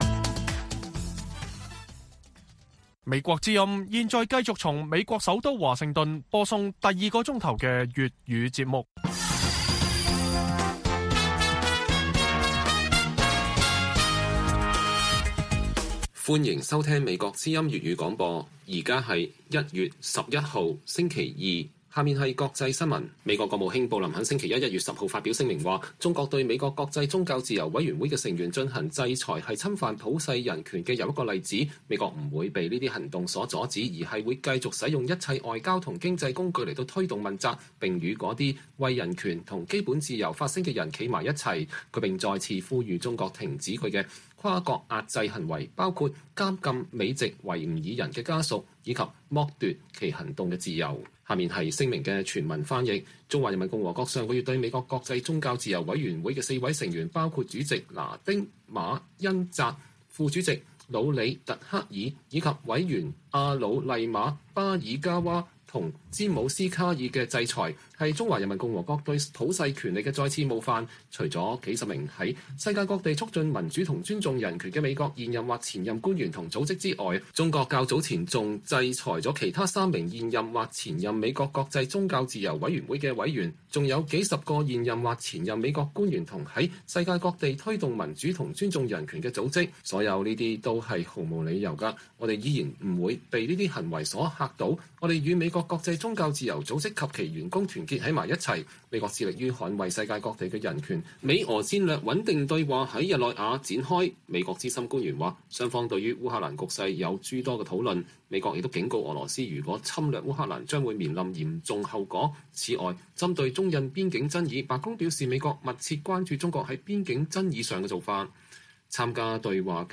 粵語新聞 晚上10-11點
北京時間每晚10－11點 (1400-1500 UTC)粵語廣播節目。內容包括國際新聞、時事經緯和社論。